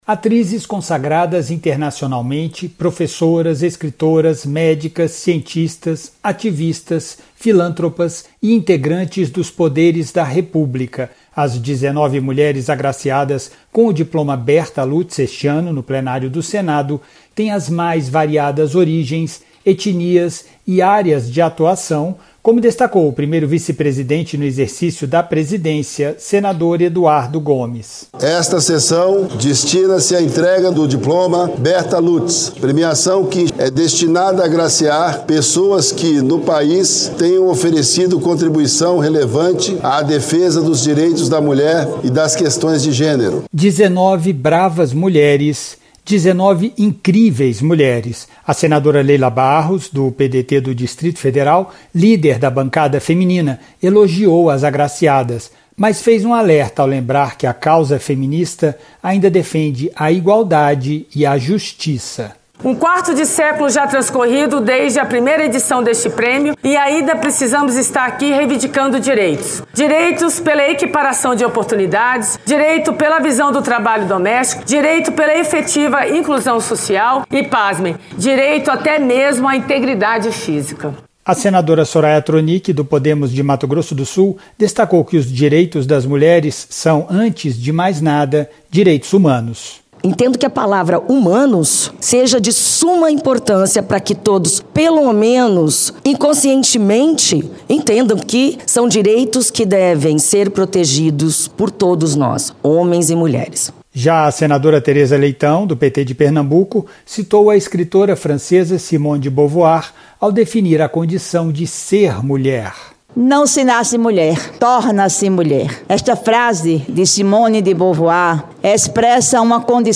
Em sessão de premiações e condecorações no Plenário, 19 mulheres foram agraciadas com o prêmio Bertha Lutz em sua 22ª edição. O primeiro-vice-presidente do Senado, no exercício da Presidência, senador Eduardo Gomes (PL-TO), lembrou que o prêmio reconhece mulheres que tenham oferecido contribuição relevante à defesa dos direitos femininos e das questões de gênero.